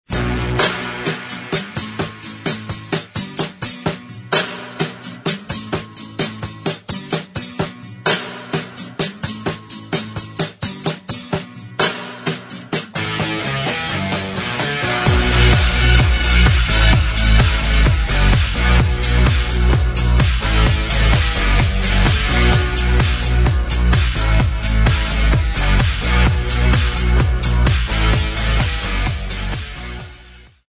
Question House song with rock riff
I need help ID:ing this house song with a (sampled) rock riff in.